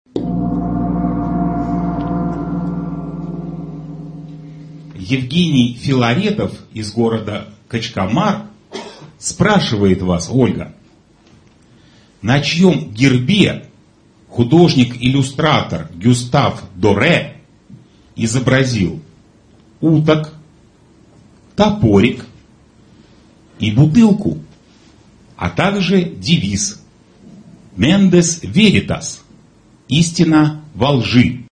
Ведущий игры неправильно озвучил название города, в то время как на экране было написано Качканар.